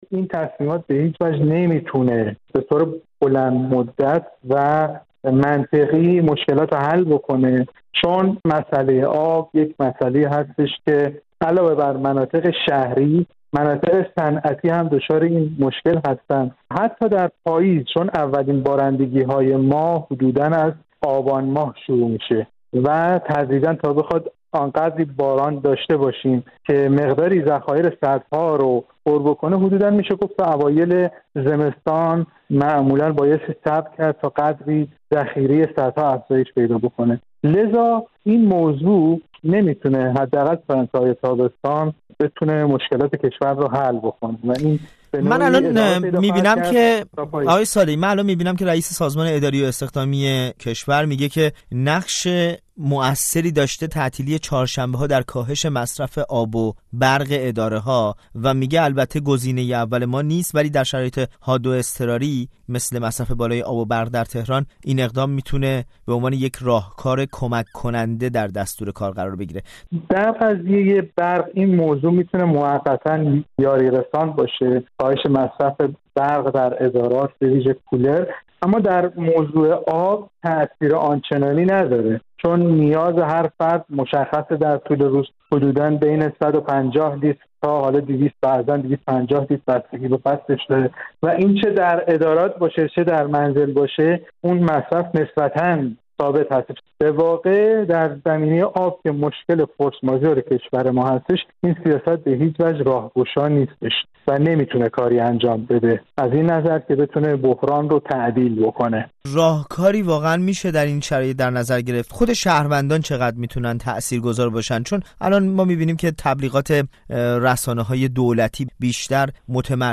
گفت‌وگو با رادیو فردا